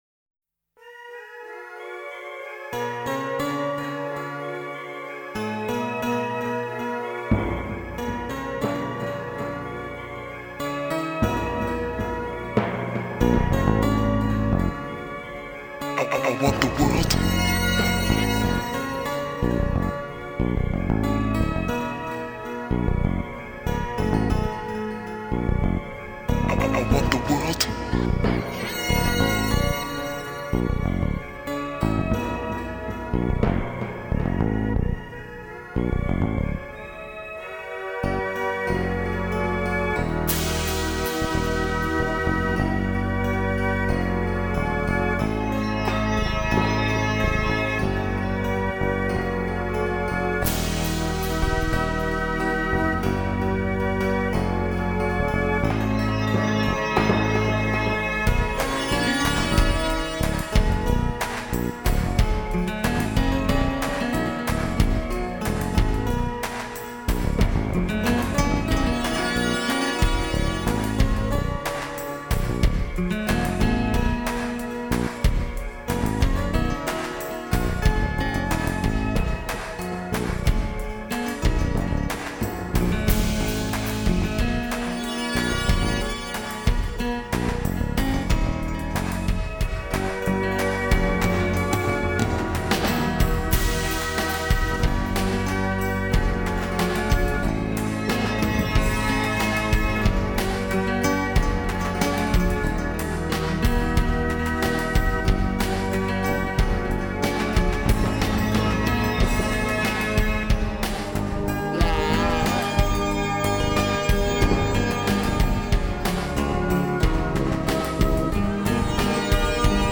ALL INSTRUMENTAL SOLO PROJECTS